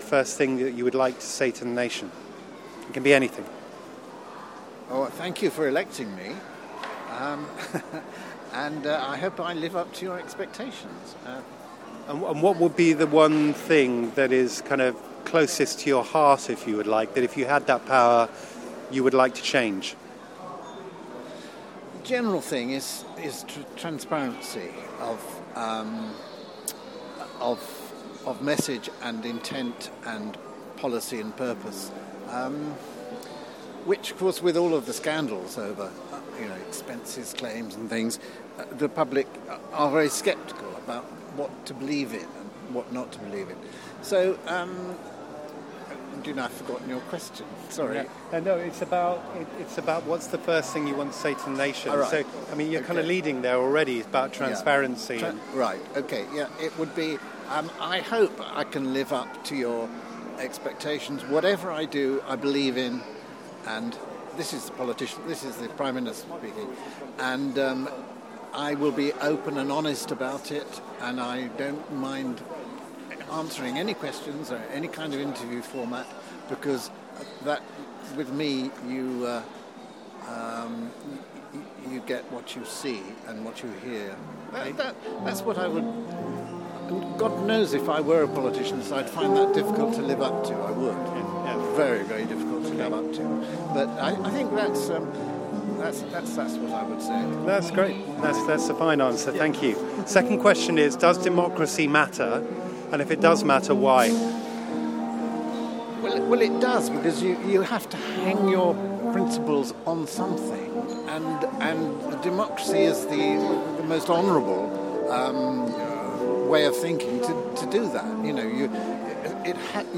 Unedited recordings from The Open Market 23/8/14